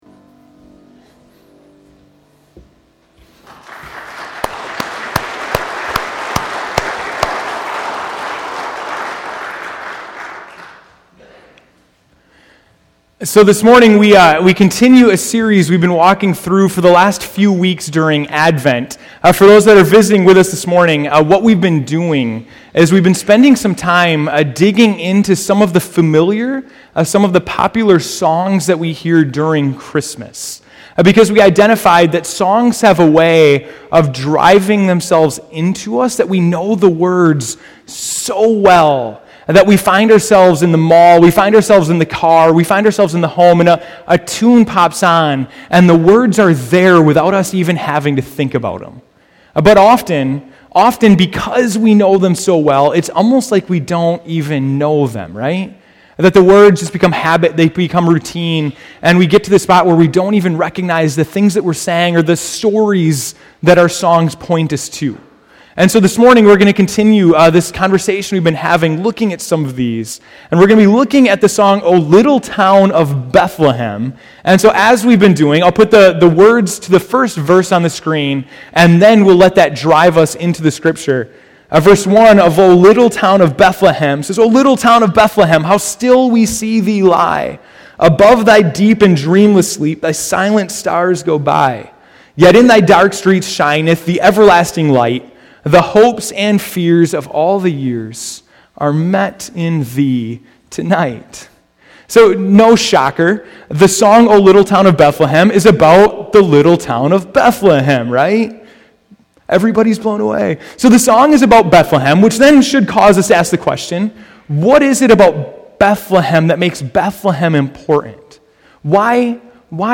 December 21, 2014 (Morning Worship)